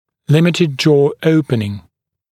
[‘lɪmɪtɪd ʤɔː ‘əupnɪŋ][‘лимитид джо: ‘оупнин]ограничение открывание рта